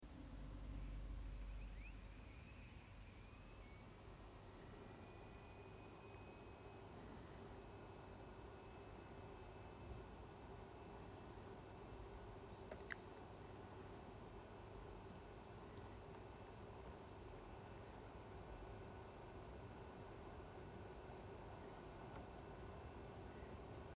Mac studio v1 fan noise - it's going to be one of the loudest Macs at idle.